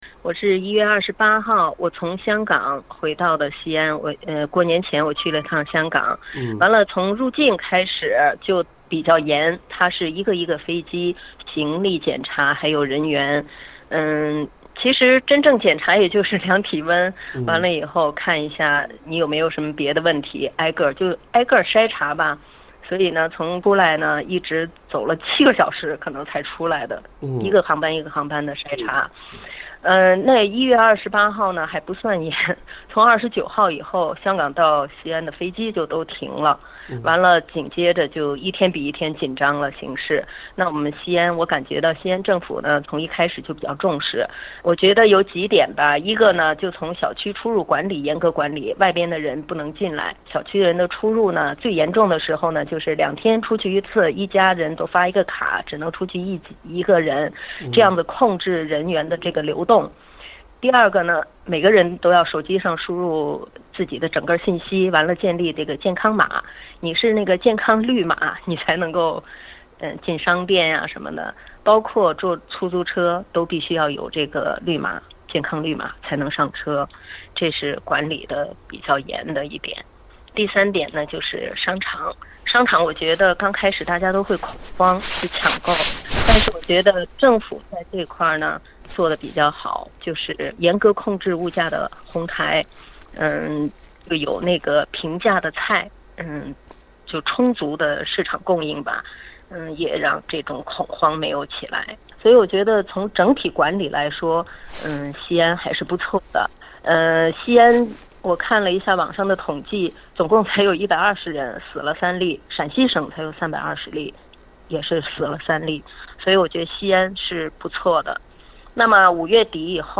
不禁令人担心，澳大利亚，特别是隔离酒店的检疫检测是否落实到位？（点击封面图片，收听采访录音）